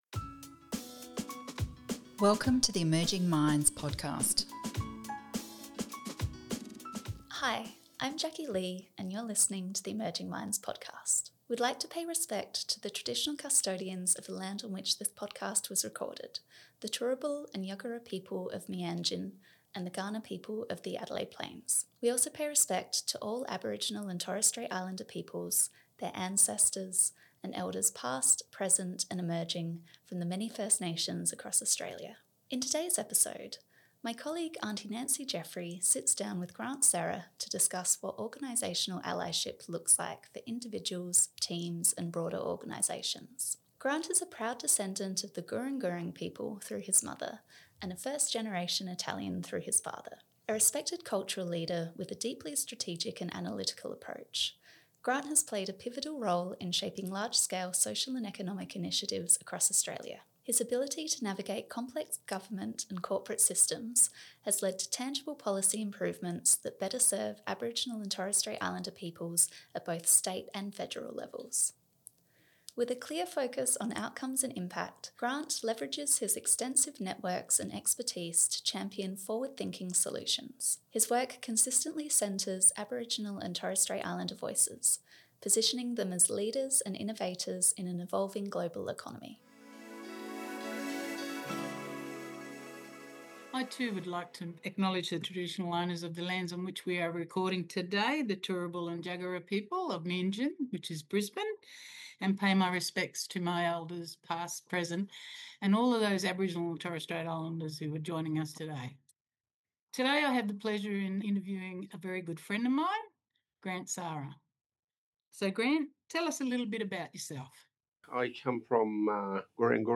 This is the first part of a conversation exploring the role of speech pathology in supporting children who’ve experienced complex trauma.